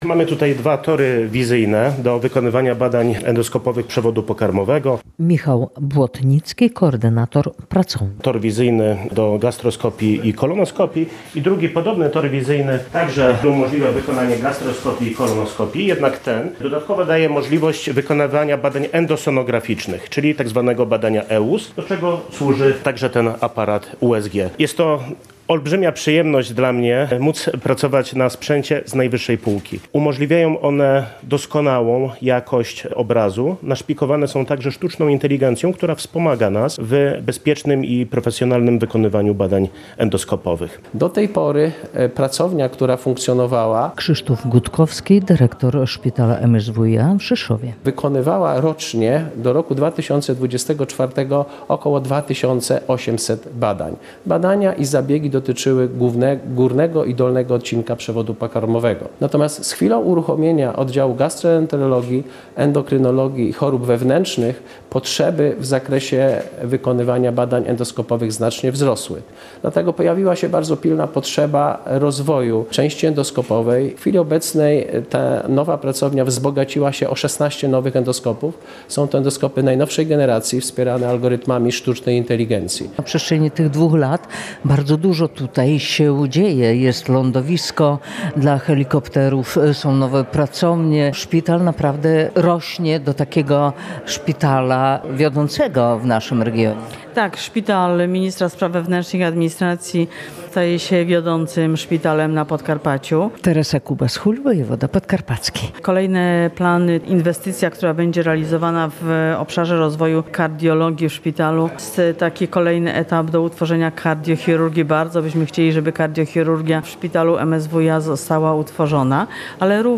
Szpital MSWiA w Rzeszowie otwiera nową Pracownię Endoskopii i Endosonografii • Relacje reporterskie • Polskie Radio Rzeszów